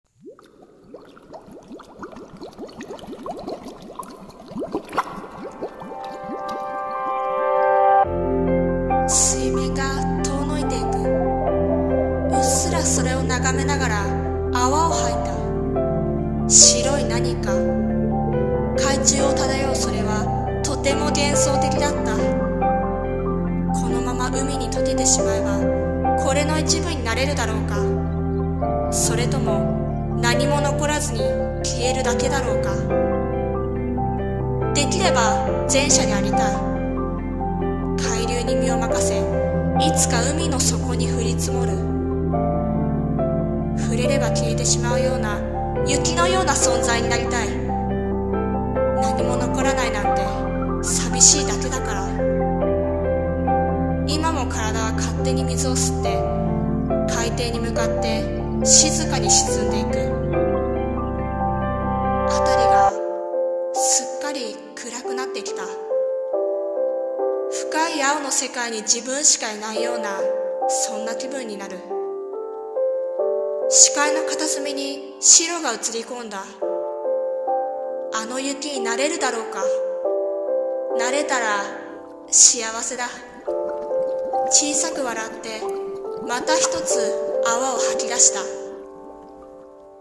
さんの投稿した曲一覧 を表示 【一人声劇】海雪にとける